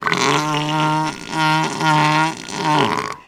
На этой странице собраны разнообразные звуки морского слона – от мощного рева самцов до нежных голосов детенышей.
Голос морского слона